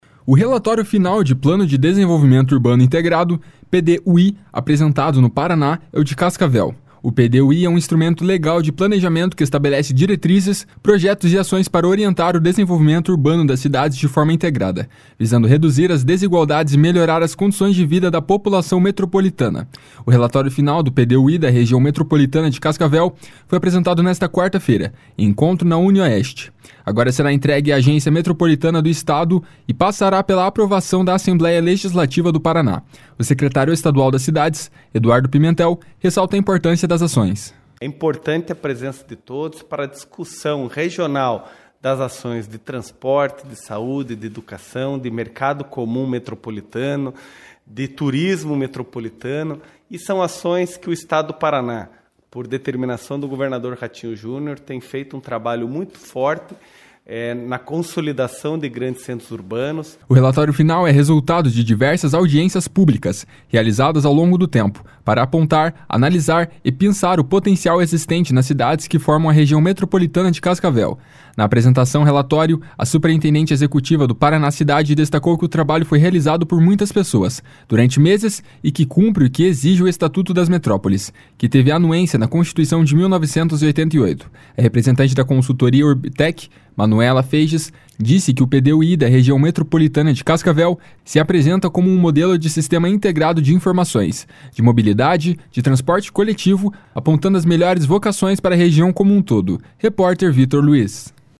O secretário estadual das Cidades, Eduardo Pimentel, ressalta a importância das ações. // SONORA EDUARDO PIMENTEL //